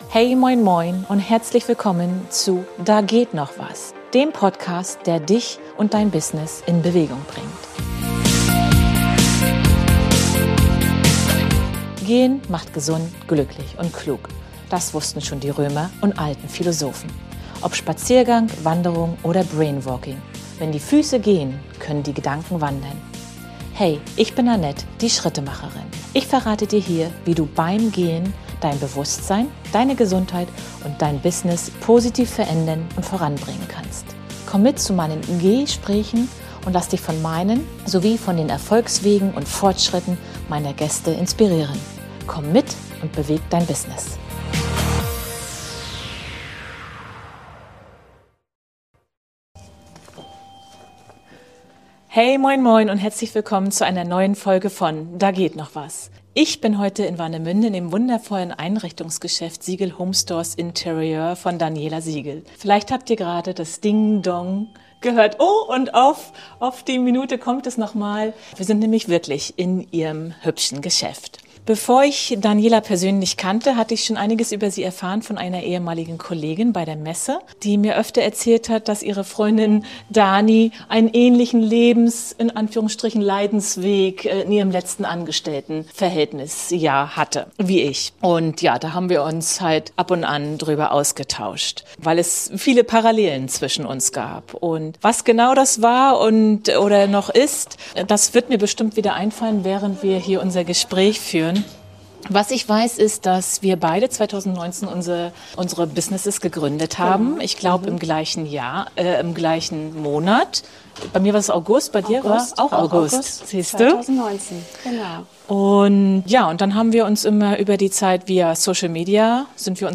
Zuversichtlich trotz Schwierigkeiten - Gespräch